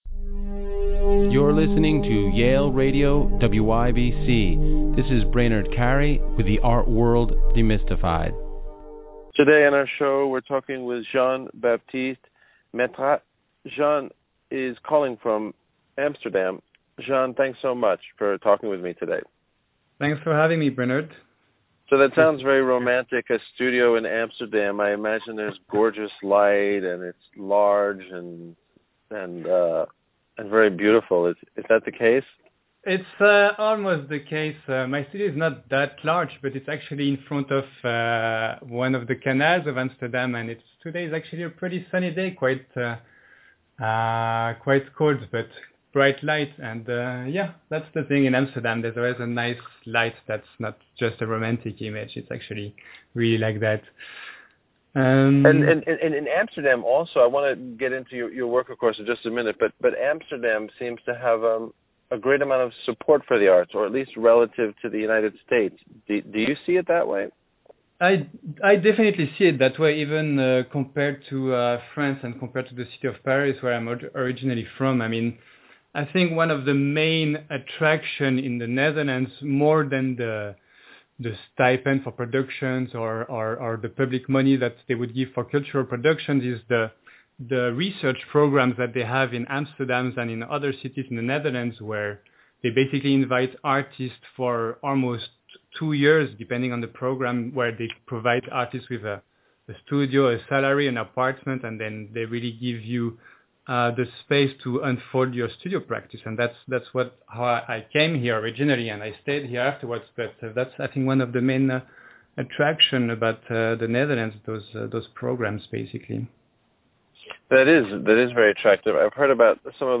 Interview from Yale Radio WYBCX